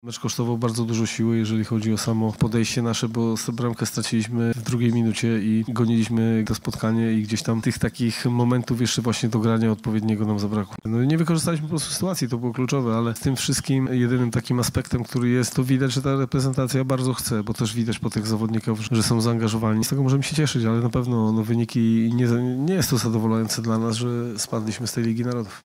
-mówi Michał Probierz, selekcjoner reprezentacji Polski.